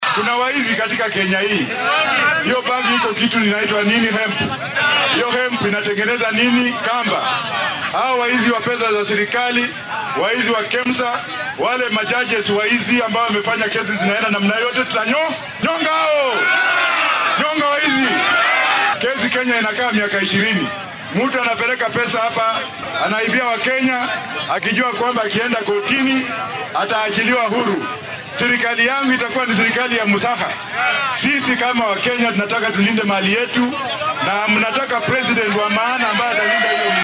Arrintan ayuu sheegay xilli uu ololihiisa siyaasadeed geeyay ismaamulka Kirinyaga ee gobolka bartamaha dalka.